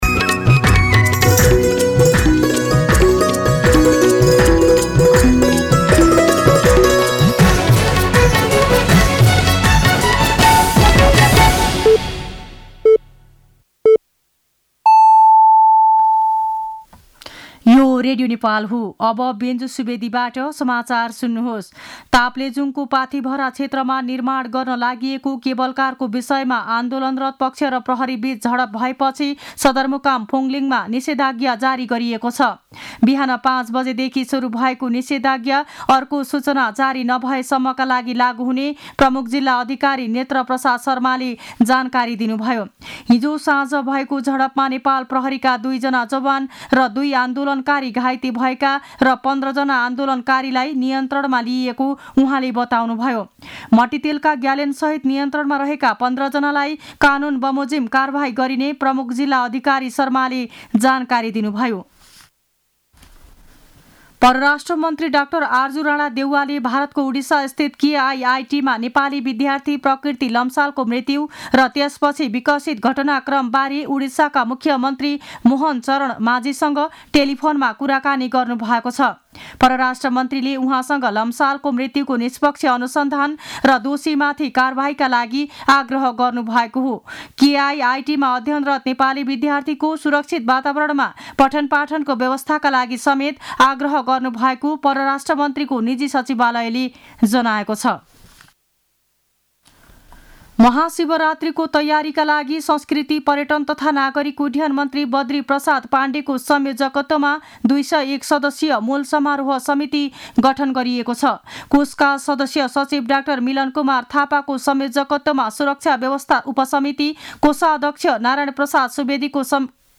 दिउँसो १ बजेको नेपाली समाचार : १२ फागुन , २०८१
1-pm-Nepali-News-1.mp3